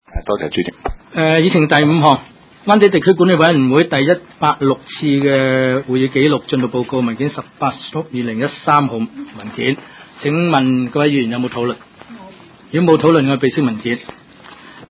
区议会大会的录音记录
湾仔区议会会议室